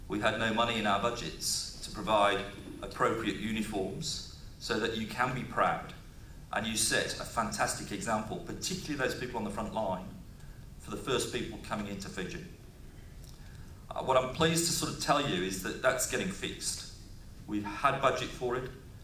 Speaking at the International Customs Day celebration today